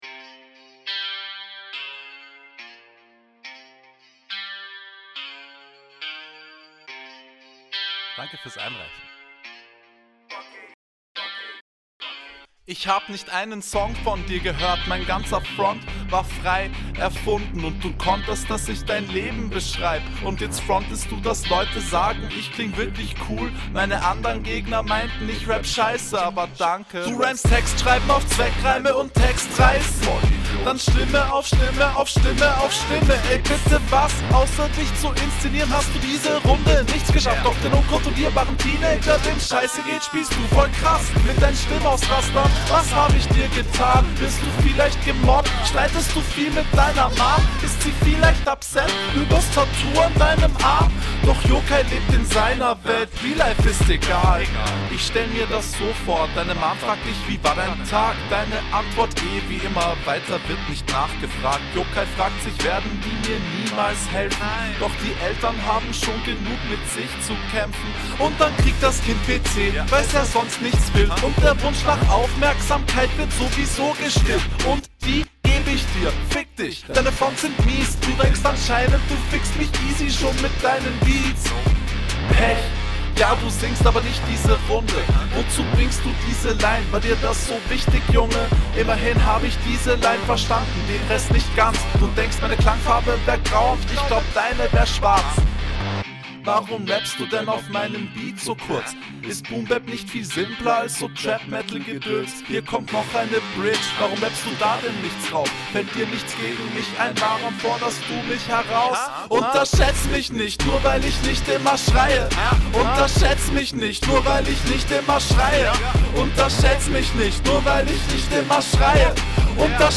Kommst ebenfalls gut auf dem Beat.